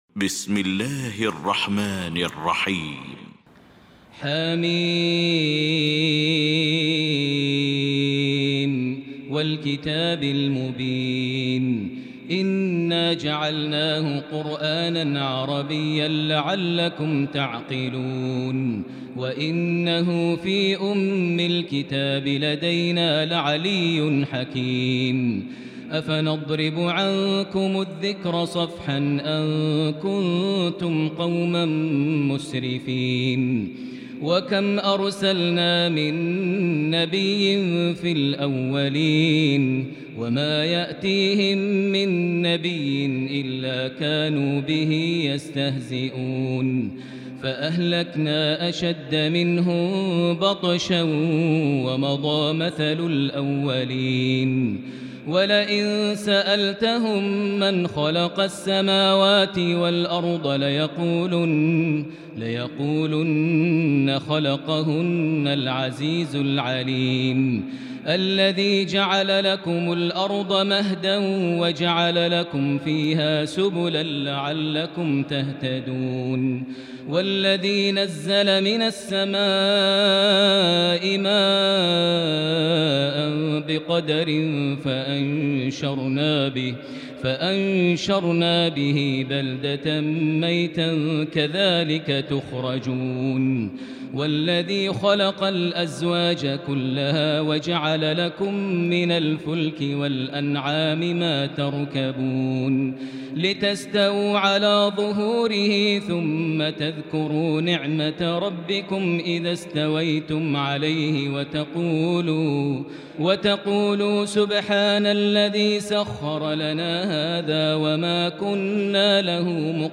المكان: المسجد الحرام الشيخ: فضيلة الشيخ ماهر المعيقلي فضيلة الشيخ ماهر المعيقلي الزخرف